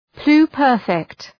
Προφορά
{plu:’pɜ:rfıkt}
pluperfect.mp3